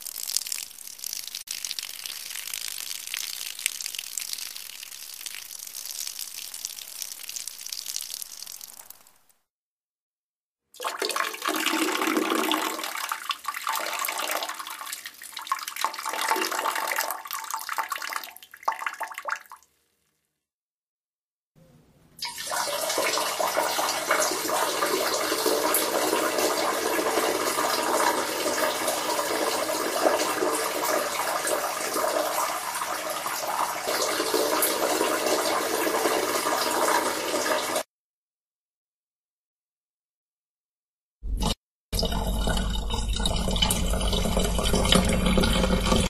Sonneries » Sons - Effets Sonores » bruitage Urine Pipi